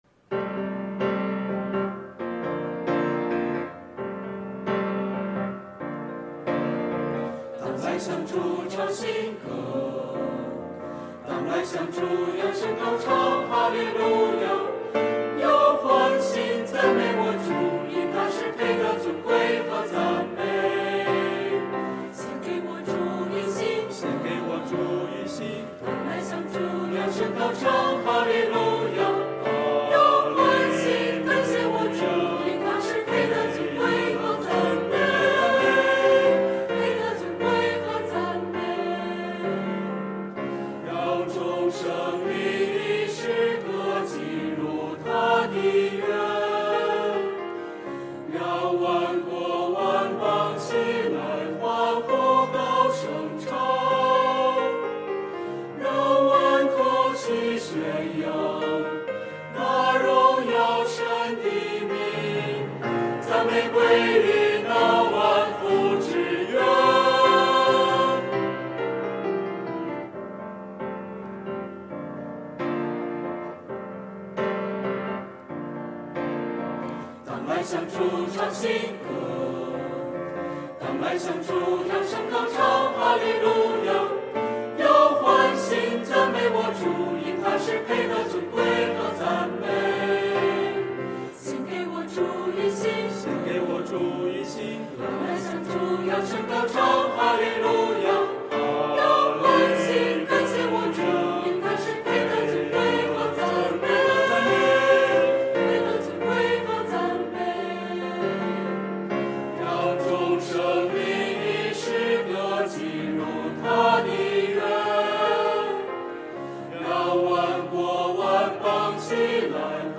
团契名称: 青年、迦密诗班
诗班献诗